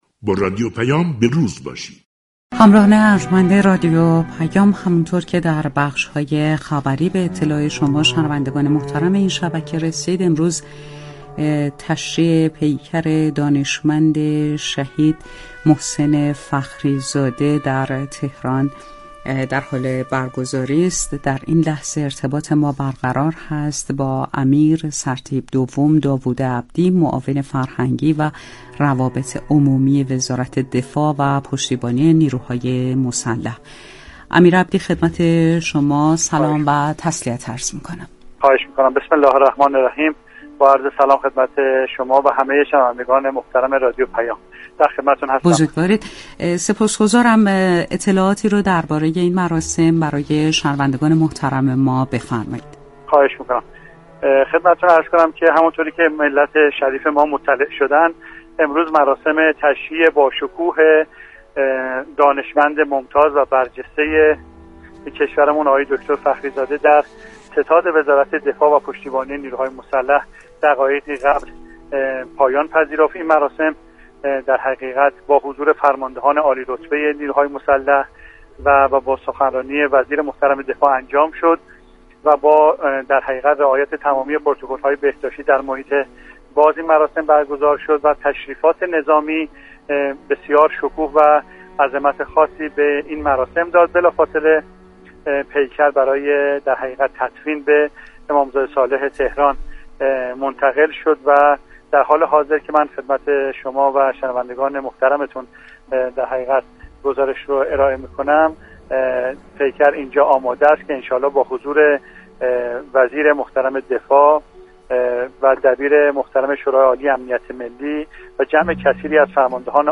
درگفتگو با رادیو پیام